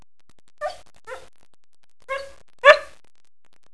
barkinged.wav